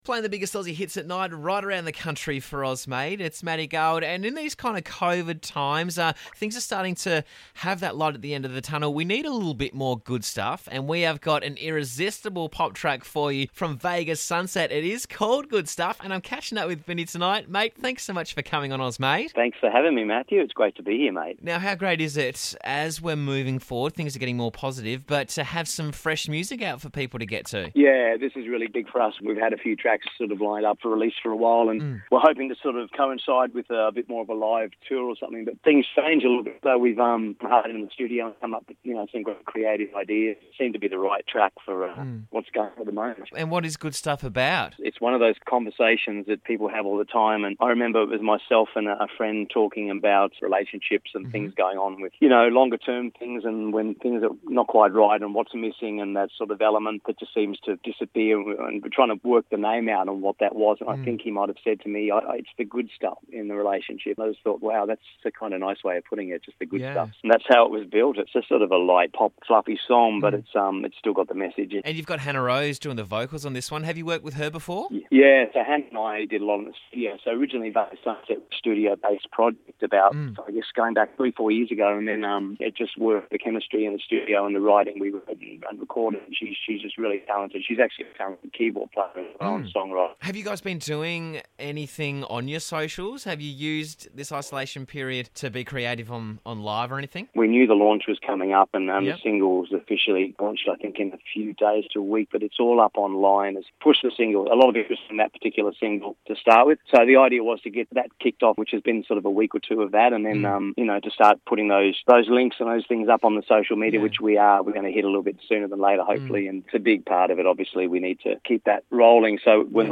an enthralling vocal